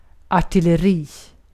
Ääntäminen
US : IPA : /ɑɹ.ˈtɪl.ə.ɹi/ RP : IPA : /ɑː.ˈtɪl.ə.ɹi/